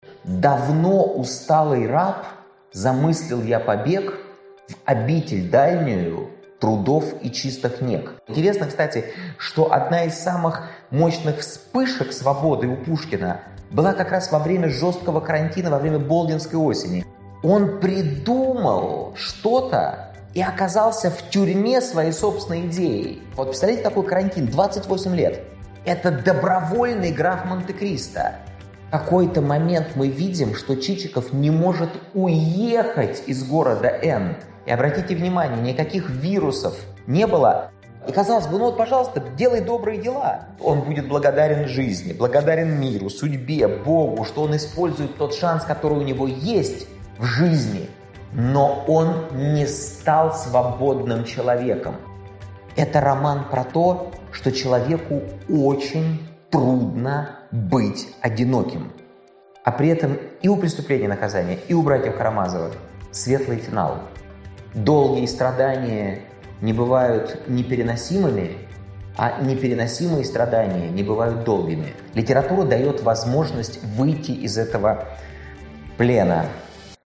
Аудиокнига Выходи из плена, или побег к свободе | Библиотека аудиокниг